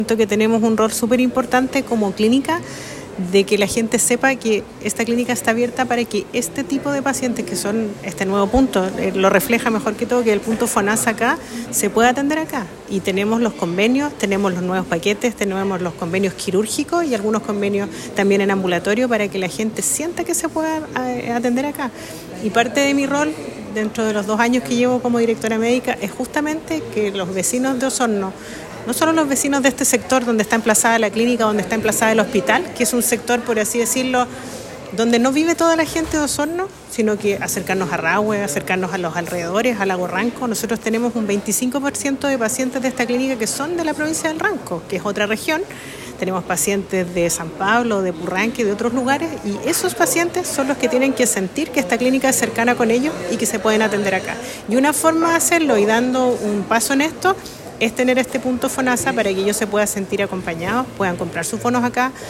La inauguración oficial de este nuevo Punto de Atención Fonasa se realizó con un evento encabezado por directivos de la clínica y representantes de Fonasa.